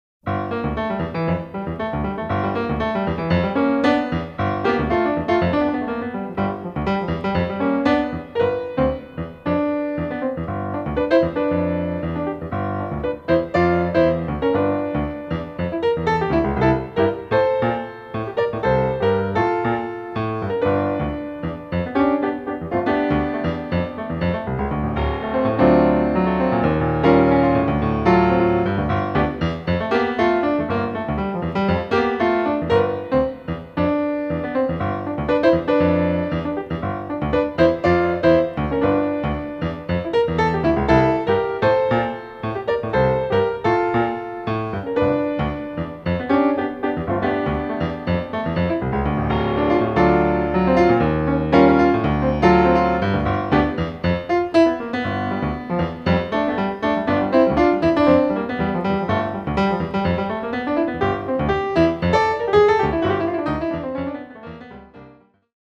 He gets a big, full sound…has a smooth, rich voice…”